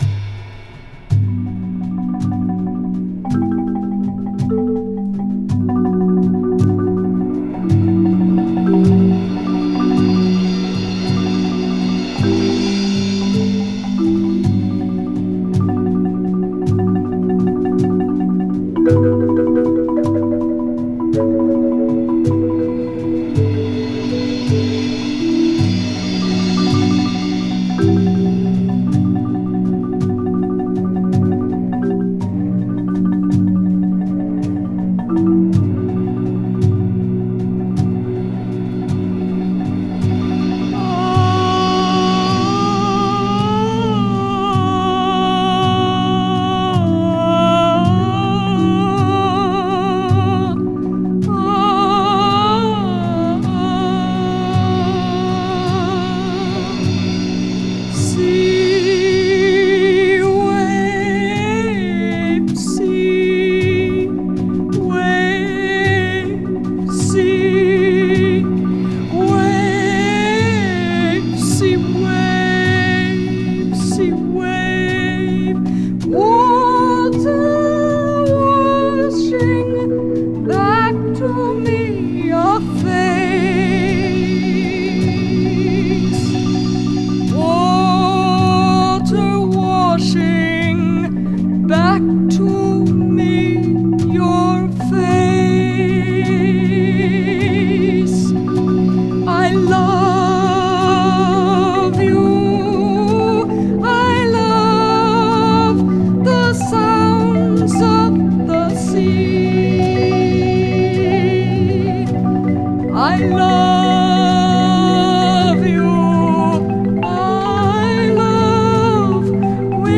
It is an intense voice.